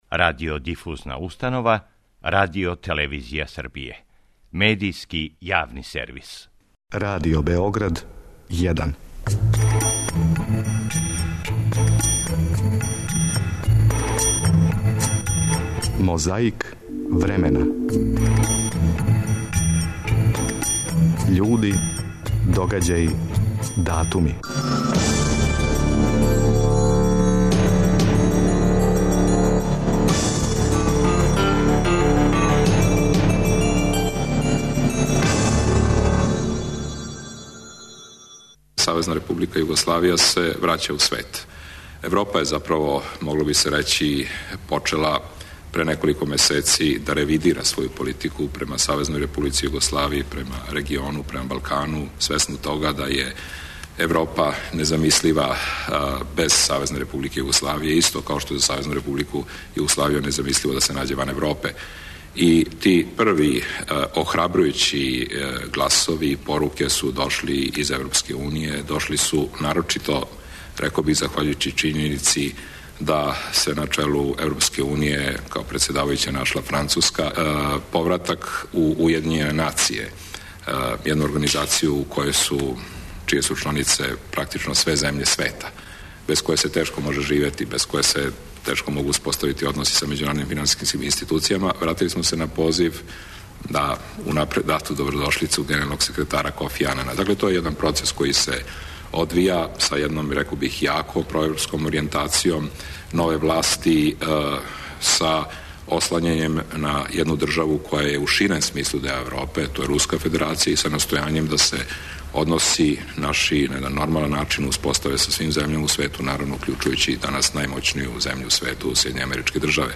Извођењем драме "Чекајући годоа", Самјуела Бекета, београдско позориште Атеље 212 почело је са радом 12. новембра 1956. године. У име свих година, у име свих који су били на сцени, у име свих који су били у публици, слушаћемо Зорана Радмиловића као Радована III.